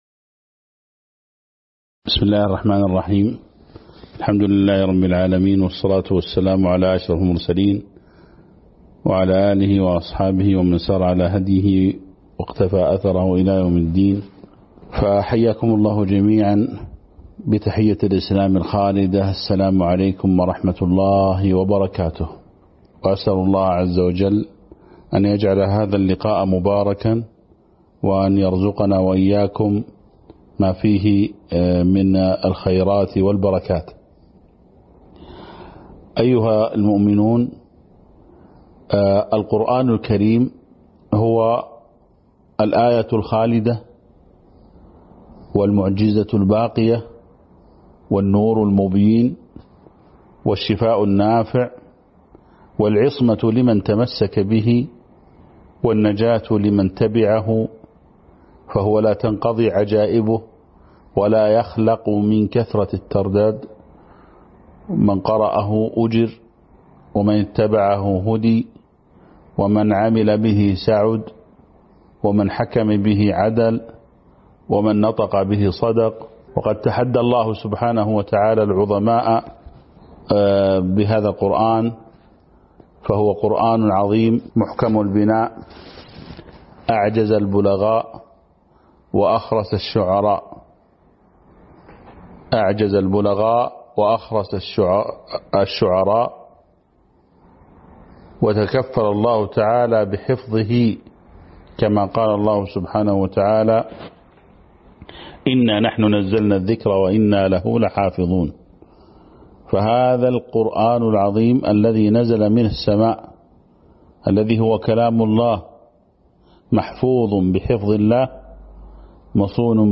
تاريخ النشر ٩ جمادى الآخرة ١٤٤٣ هـ المكان: المسجد النبوي الشيخ